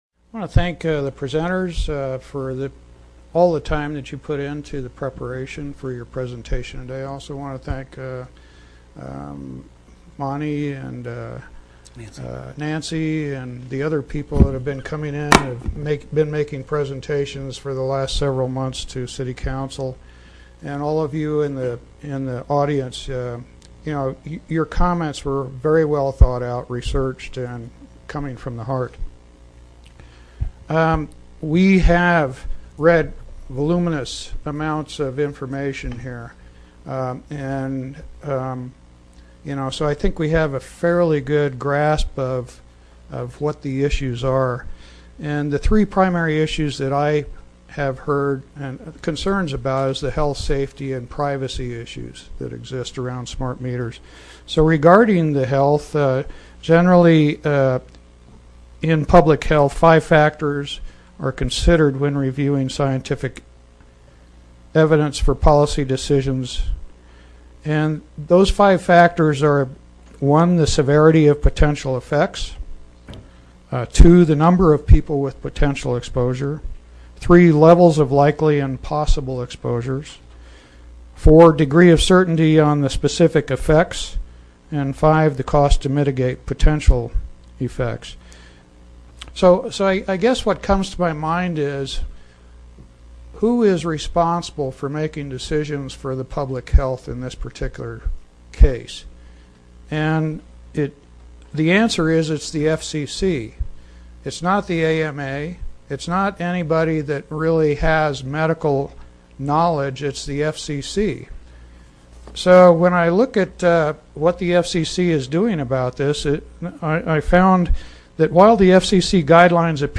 Sedona AZ– On Wednesday, June 19, 2013, the Sedona City Council held a Special Council Meeting to direct staff’s next steps regarding Arizona Public Service company’s plan to install smart meters in Sedona.
The total audio clip is about seven (7) minutes long and concludes with a vote by the City Council to request a City-wide opt-out for smart meters in the town of about 10,000 residents.
mayor-sedona-and-motion.mp3